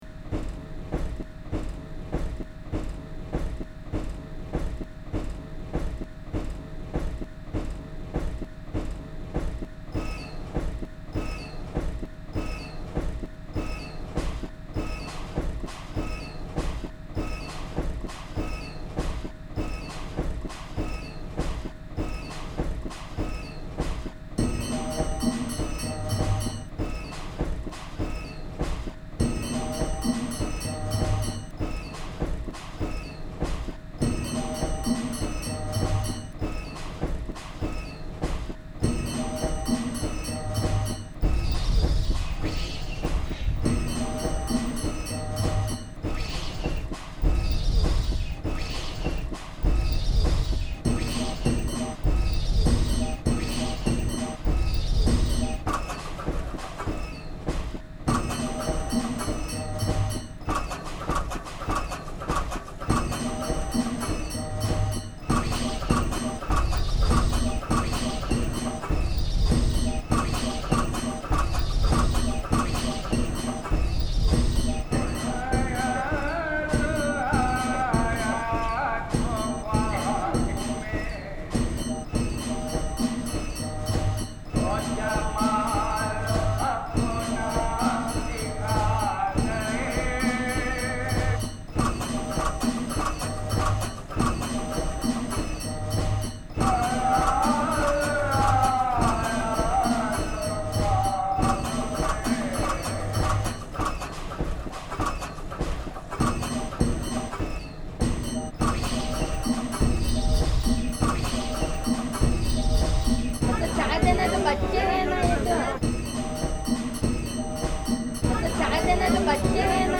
One enters a tent and listens to field recordings remixed into place-specific musical compositions, while looking at abstracted images of the locations the site-specific sounds originate from.